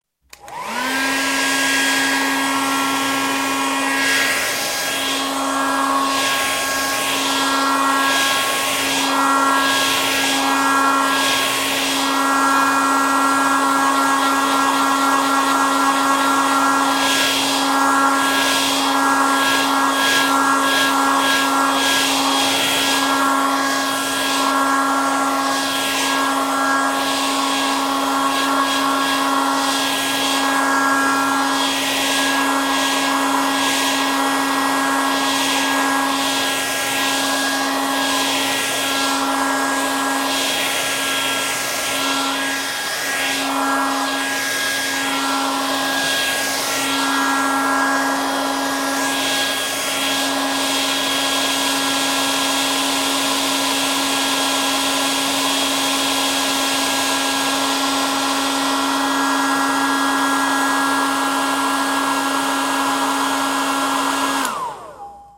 Hair Dryer - Фен
Отличного качества, без посторонних шумов.
174_fen.mp3